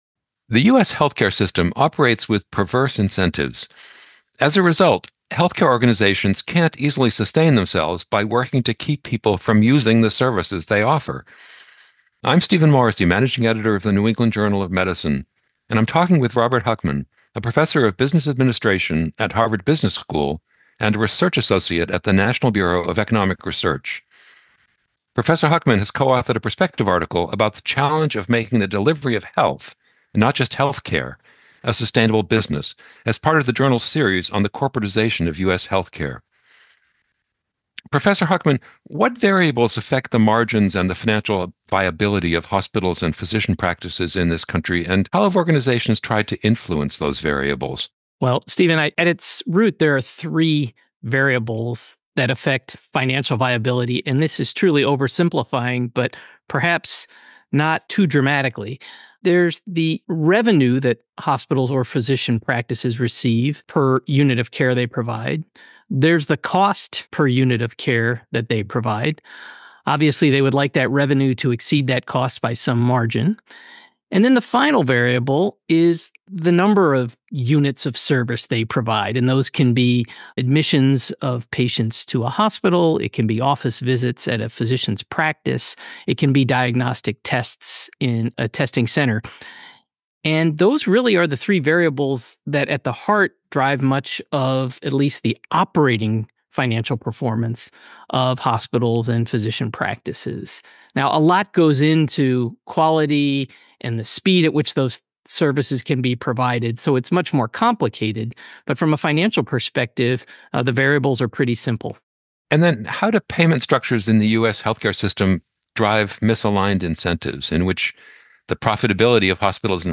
Jeanne Marrazzo is the director of the National Institute of Allergy and Infectious Diseases.
Audio Interview: The Current State of Avian Influenza.